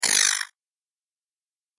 جلوه های صوتی
دانلود صدای زنگ 14 از ساعد نیوز با لینک مستقیم و کیفیت بالا